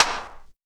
• Airy Snare One Shot D Key 25.wav
Royality free snare tuned to the D note. Loudest frequency: 2918Hz
airy-snare-one-shot-d-key-25-gw9.wav